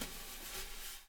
SND DRUMAA-R.wav